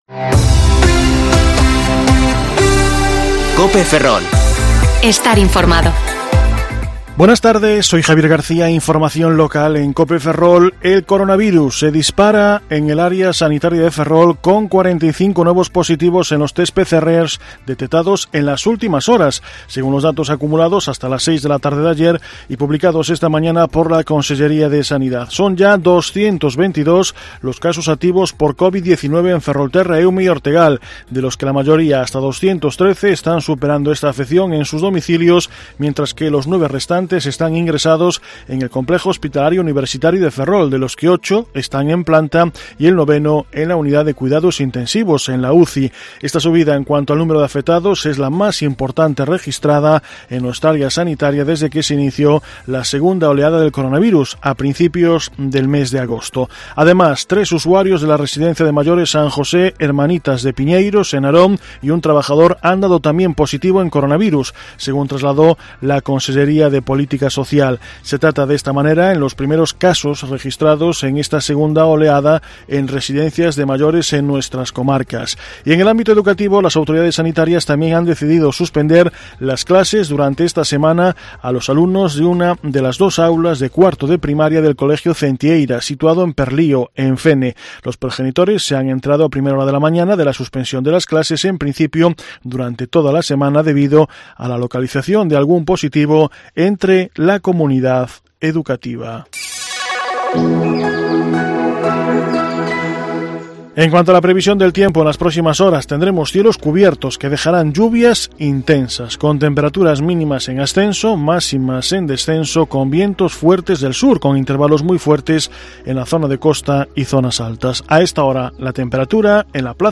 Informativo Mediodía COPE Ferrol 19/10/2020 (De 14,20 a 14,30 horas)